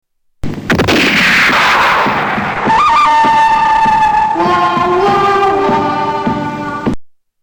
Выстрел под музыку